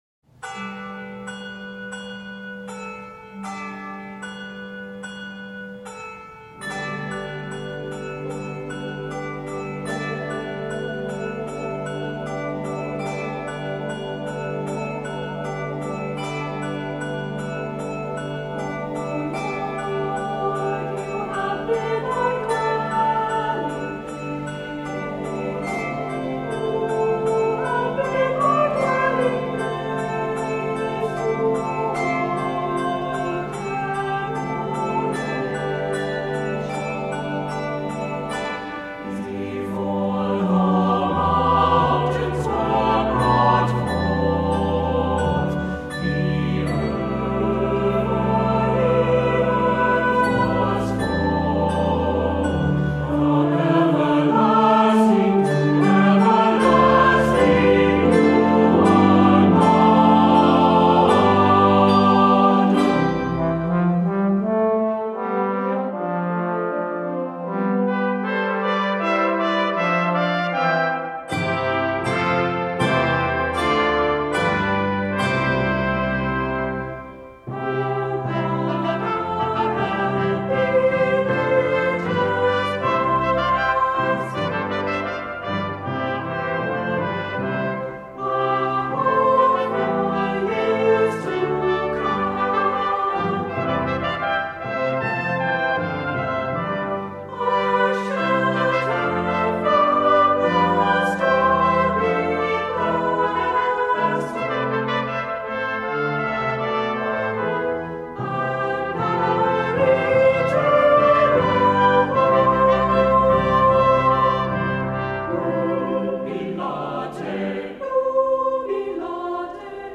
Voicing: Assembly